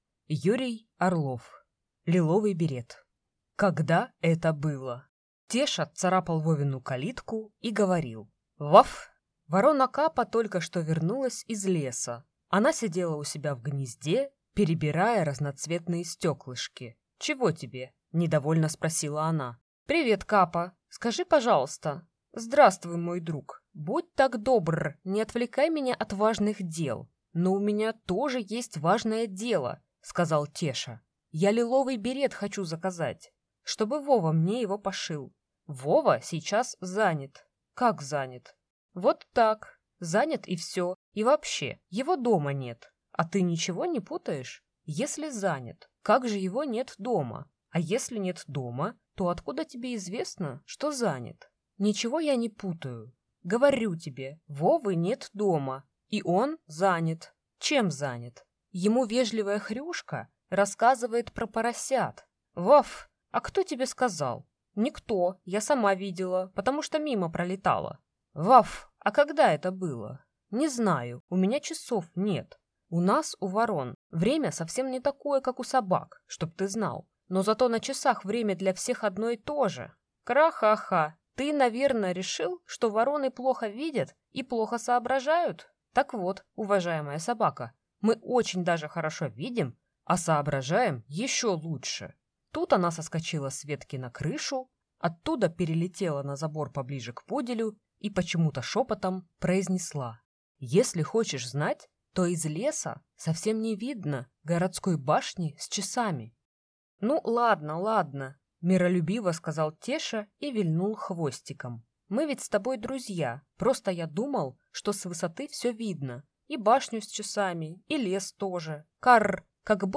Аудиокнига Лиловый берет | Библиотека аудиокниг